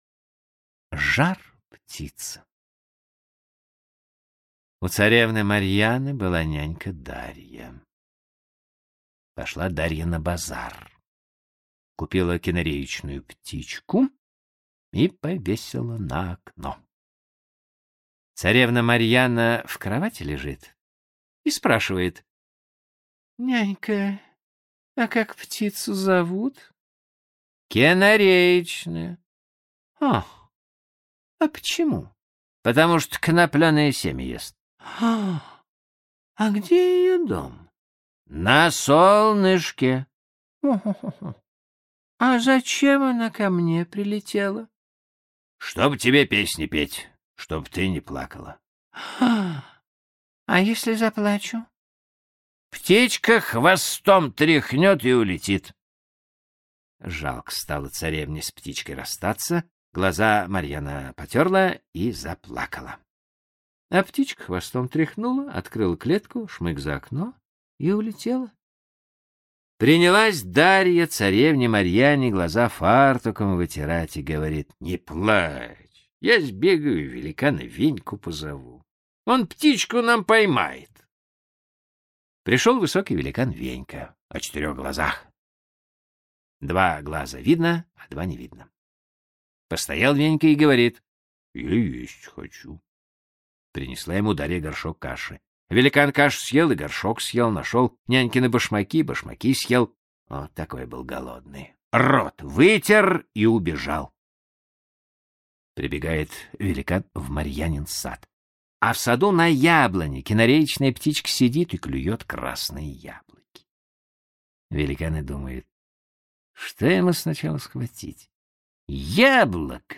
Жар-птица – Толстой А.Н. (аудиоверсия)